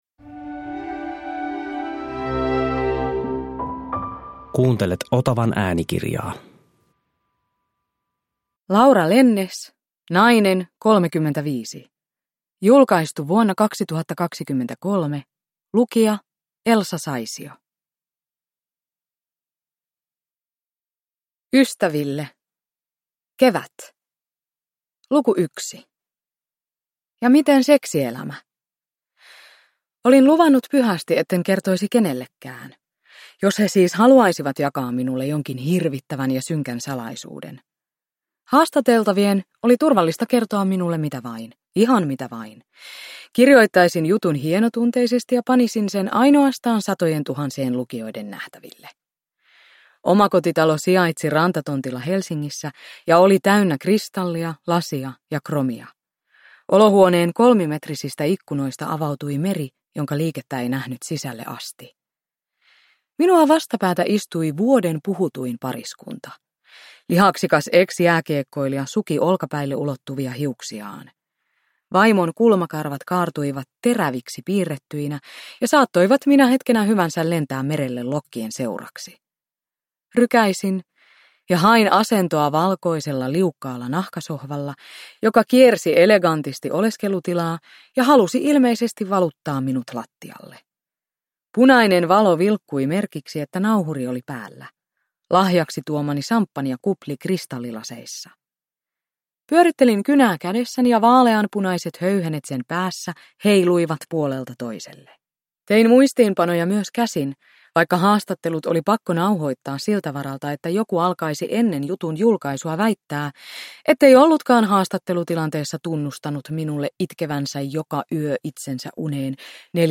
Nainen, 35 – Ljudbok
Uppläsare: Elsa Saisio